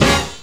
JAZZ STAB 27.wav